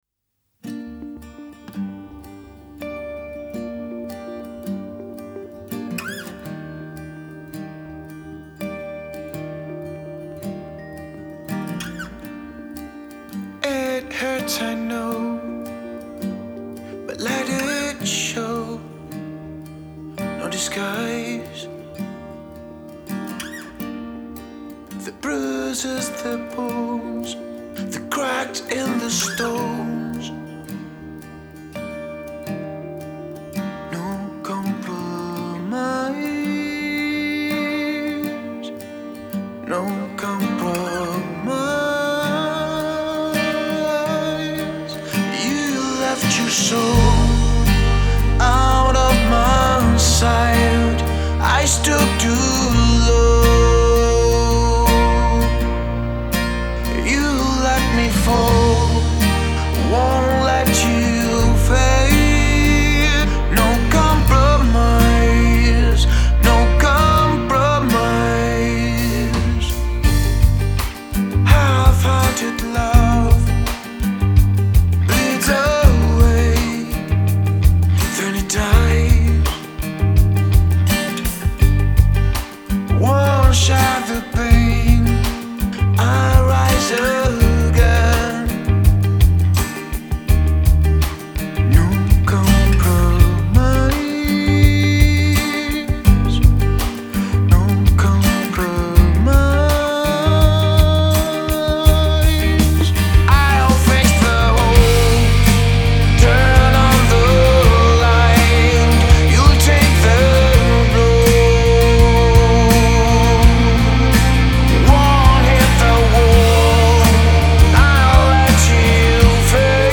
a four piece Rock band based in London